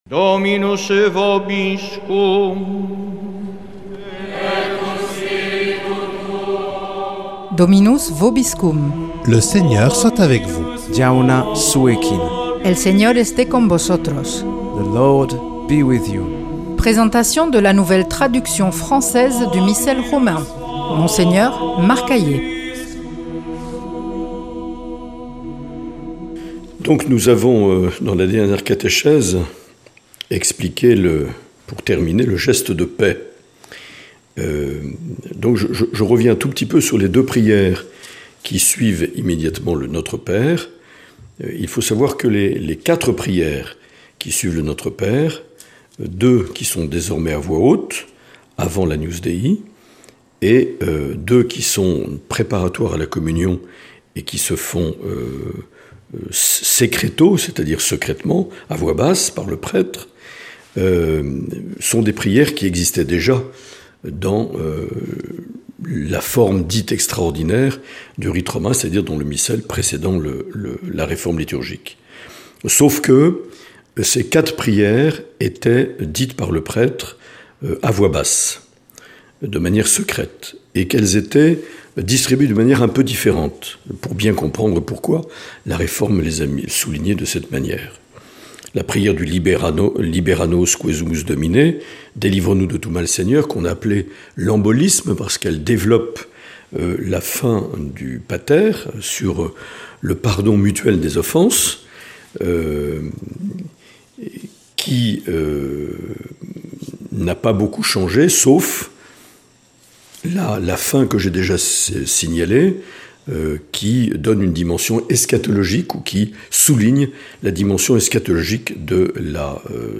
Présentation de la nouvelle traduction française du Missel Romain par Mgr Marc Aillet